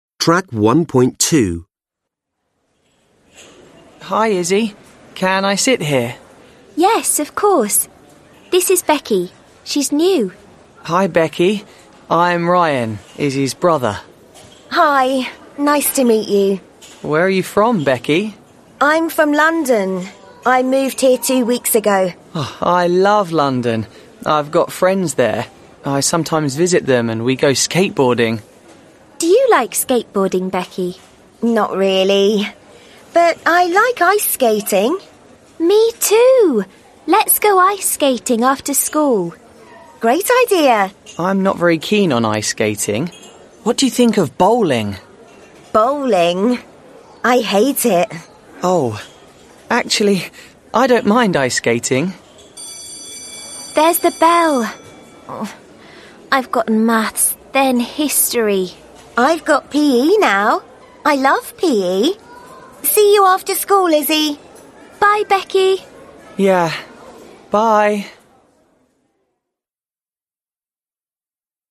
2 (trang 6 Tiếng Anh lớp 10) Read and listen to the dialogue. Find he names of the people in the photo. (Đọc và nghe đoạn hội thoại. Tìm tên của từng người trong bức ảnh)